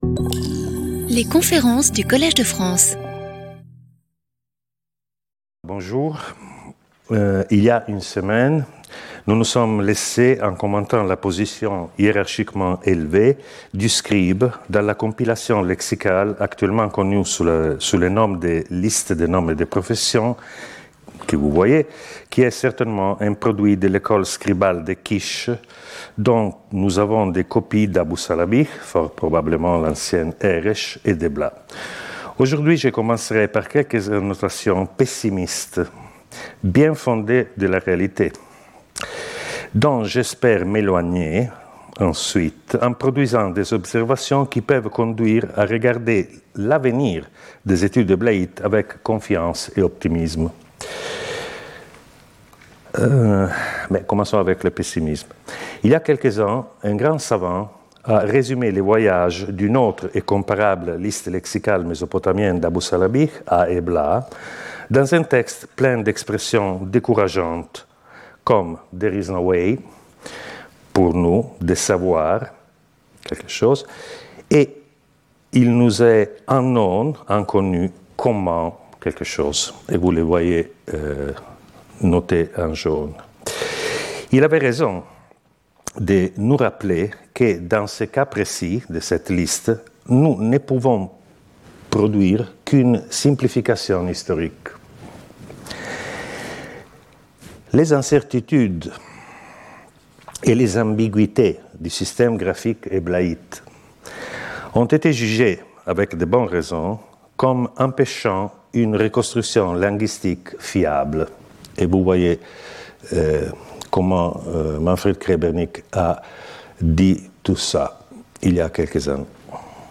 Conférencier invité